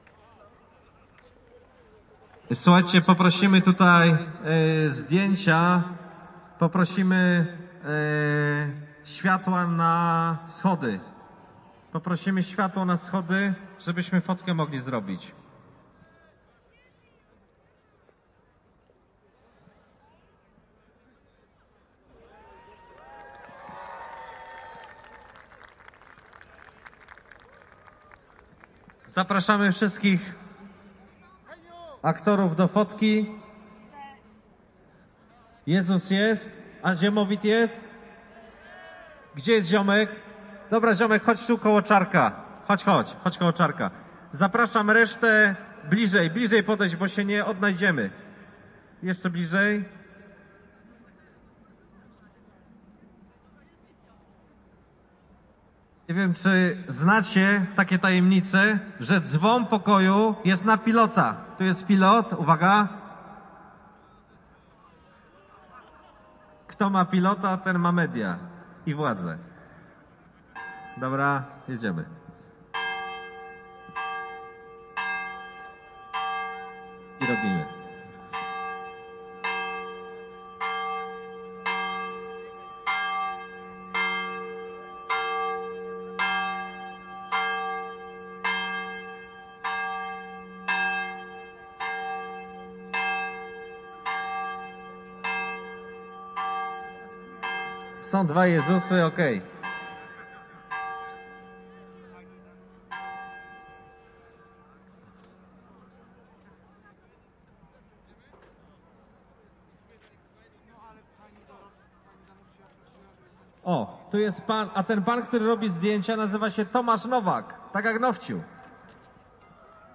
波兹南医科大学新学年开学典礼。在颁发学生记录本的仪式上，有一些风琴音乐会。
标签： 典礼 合唱团 演唱会 现场记录 就职演讲 影院 器官 波兰 波兹南 歌曲 大学
声道立体声